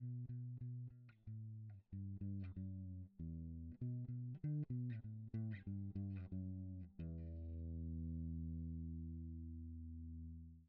Before anyone start to wonder why I have uploaded 2 files with silence...
You will have to drive your card really hard (or chain 2 buses with +6 dB in Sonar) to hear something.
Both files are exported from Sonar, 16bit 44.1 kHz from the same just recorded n00b clip (with prior lowering the clip gate). One with and another without dithering.